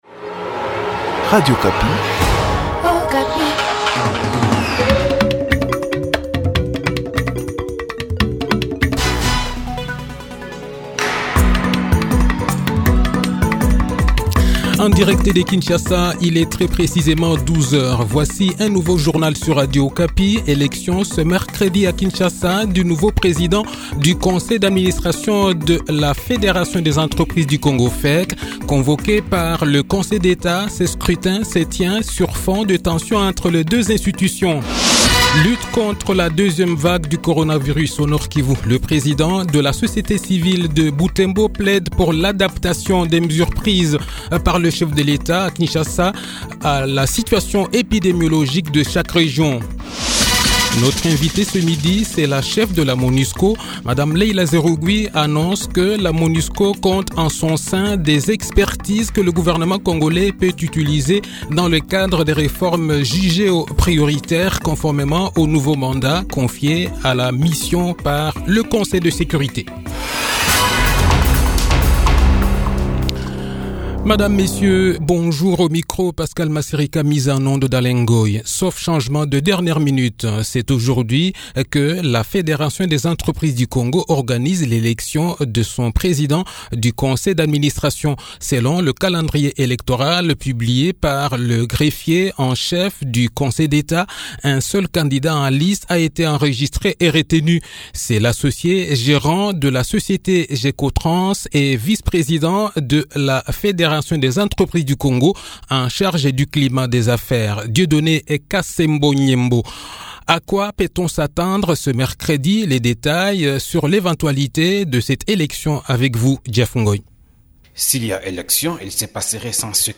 Le journal de 12 h, 23 Décembre 2020
Kinshasa-Invitée : Mme Leila Zerrougui, La Cheffe de la Monusco.